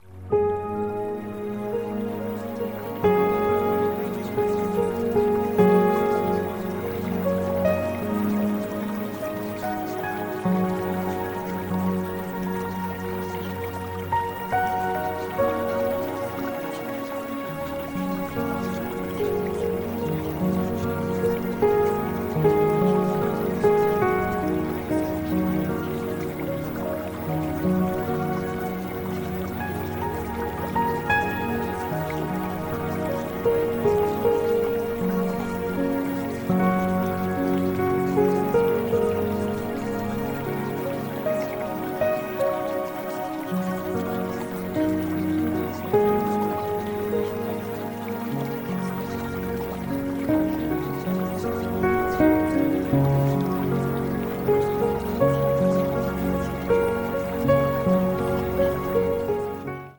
Here are samples of the music mixes.
including calming background music and nature sounds